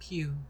snd_enemy_bullet.wav